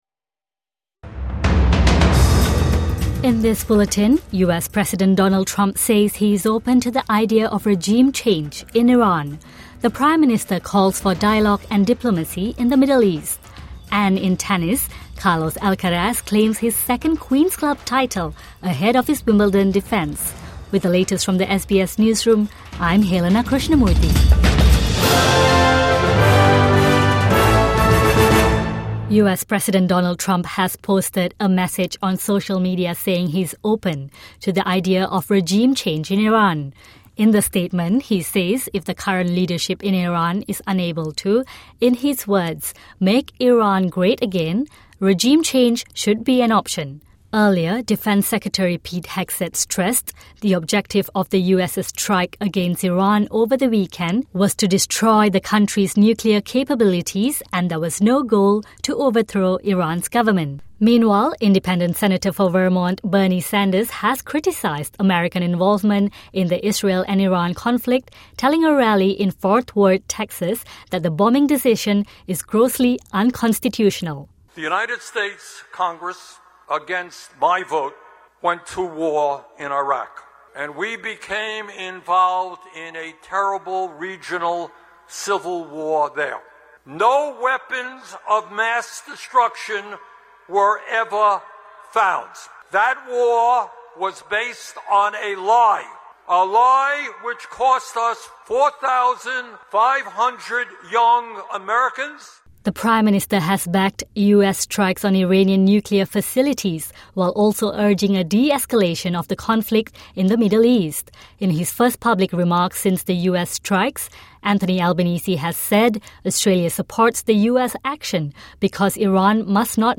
In this bulletin; US President Donald Trump says he is open to the idea of regime change in Iran, the Prime Minister calls for dialogue and diplomacy in the Middle East, and in tennis, Carlos Alcaraz claims his second Queen's Club title ahead of his Wimbledon defence.